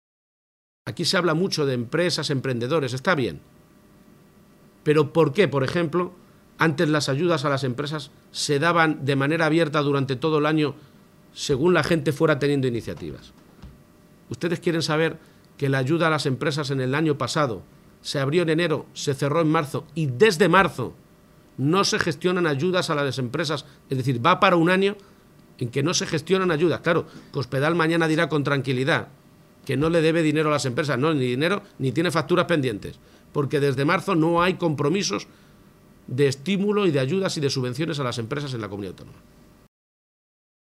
García-Page se pronunciaba de esta manera esta mañana, en Talavera de la Reina, tras reunirse con la Junta Directiva de la Federación Empresarial Talaverana (FEPEMPTA), en la ciudad de la cerámica.